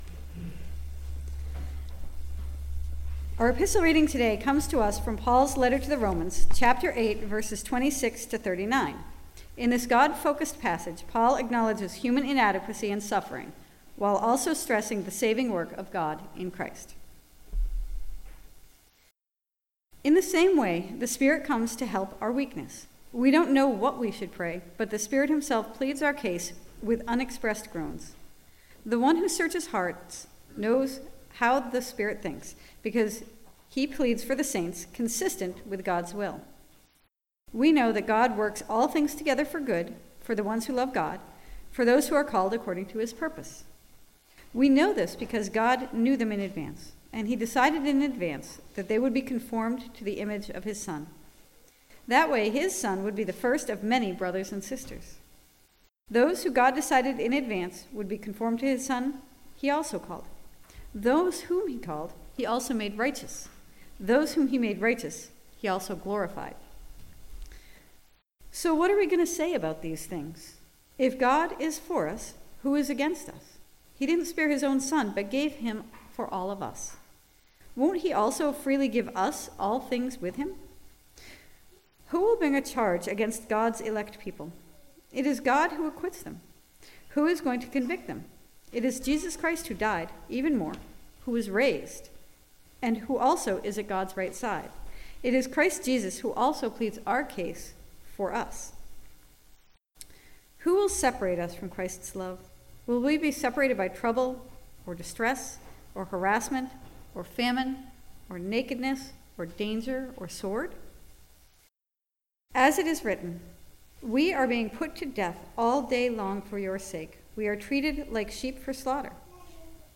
Message Delivered at: The United Church of Underhill (UCC & UMC)